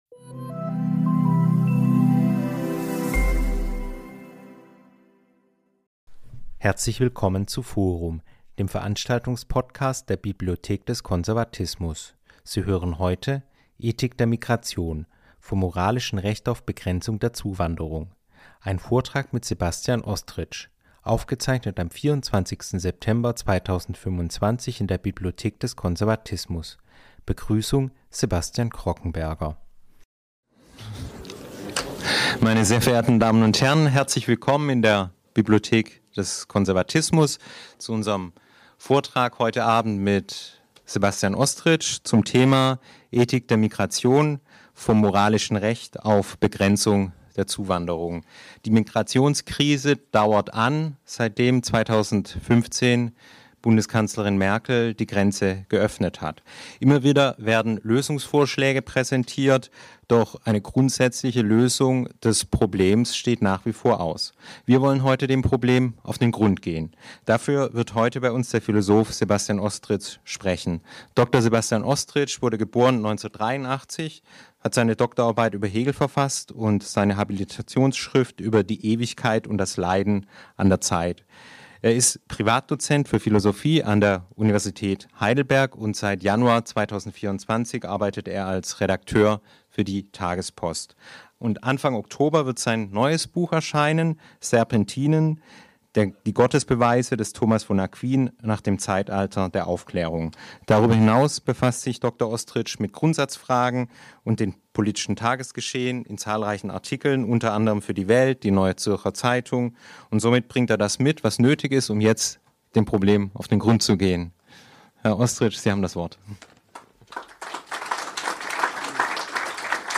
Vortrag „Ethik der Migration